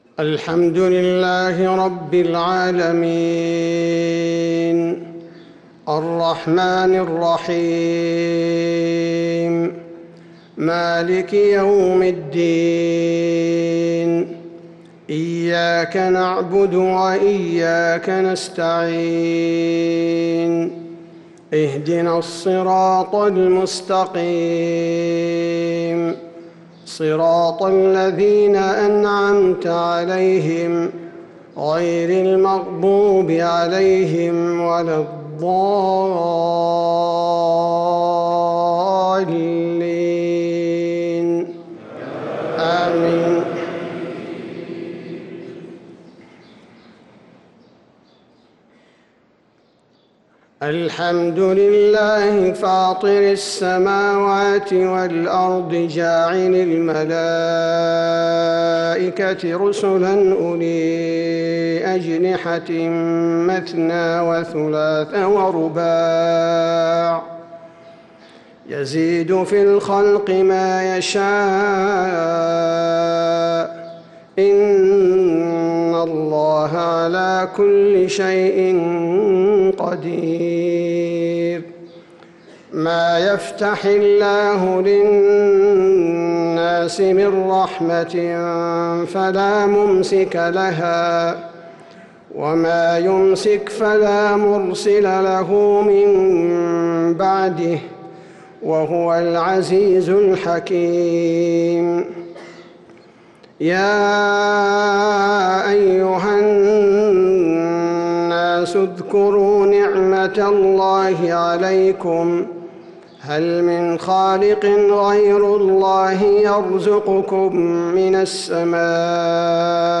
فجر السبت 8-9-1446هـ فواتح سورة فاطر 1-12 | Fajr prayer from Surat Fatir 8-3-2025 > 1446 🕌 > الفروض - تلاوات الحرمين